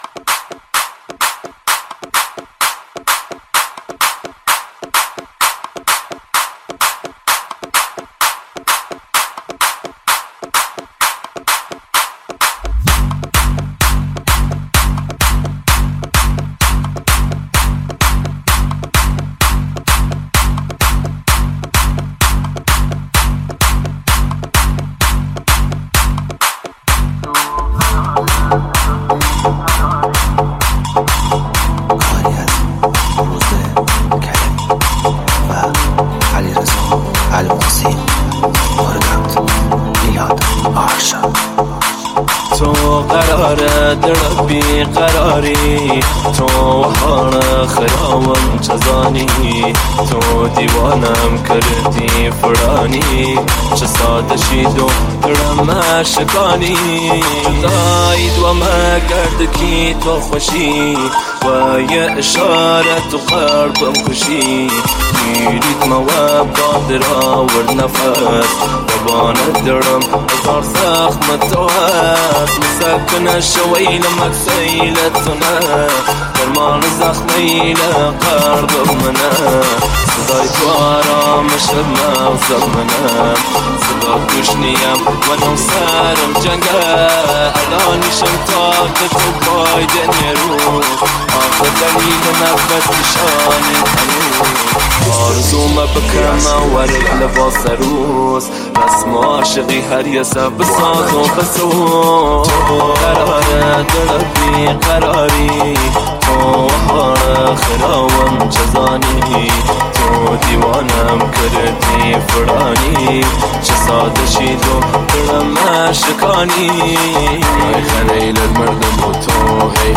نسخه ریمیکس شده